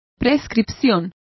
Complete with pronunciation of the translation of prescription.